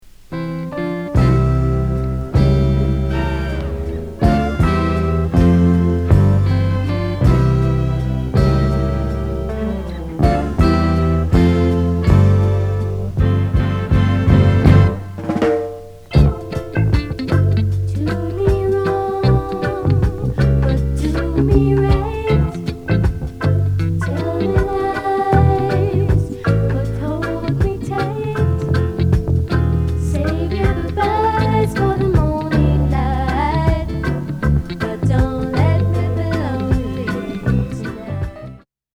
UKラヴァーズ最高峰！！そしてメロウ・ソウル・ファンも悶絶級の絶品！！
18歳の若い女子にカワイイ声で、そんな事言われちゃうとな〜〜。。
しかしほんと可愛いオーバー・ダブ・ボーカル・コーラスが最初から